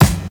Kick_24.wav